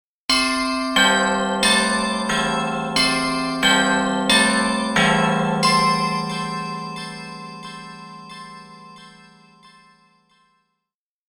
Carillon